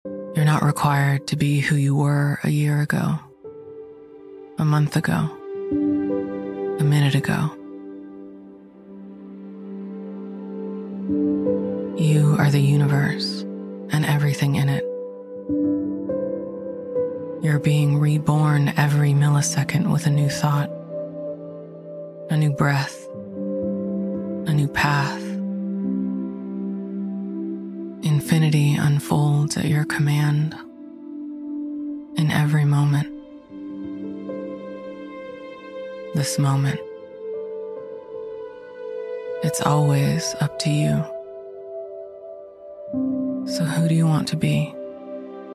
--- This is an excerpt from my latest podcast episode/meditation, out now on all platforms.